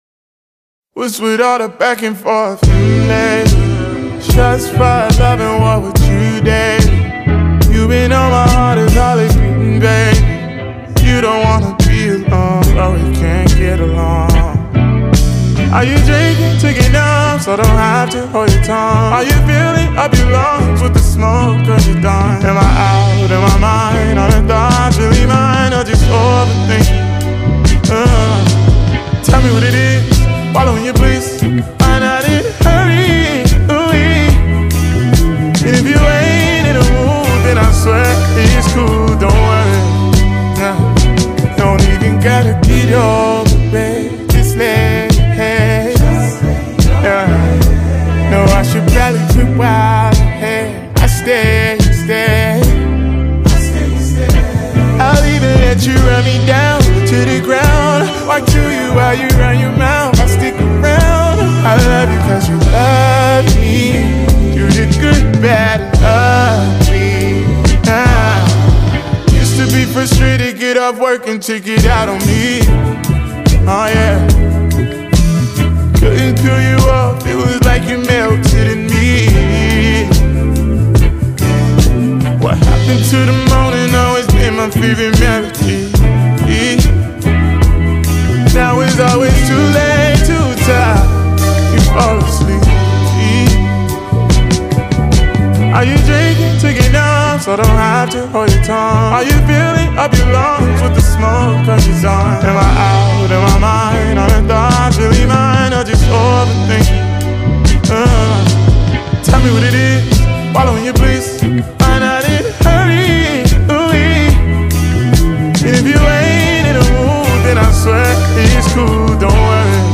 Hip Hop
American singer-songwriter